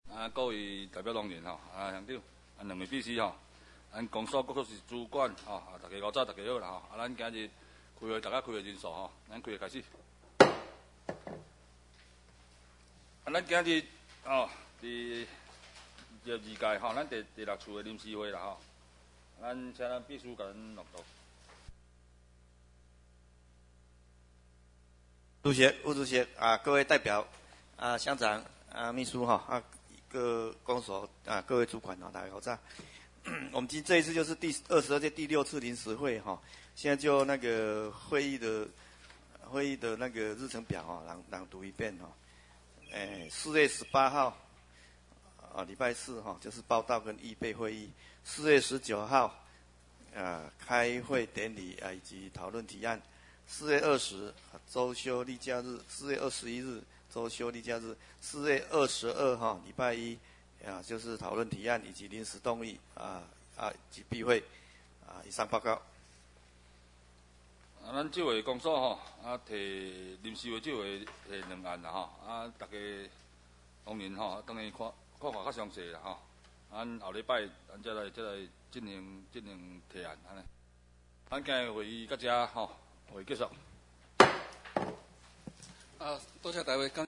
第22屆代表會議事錄音檔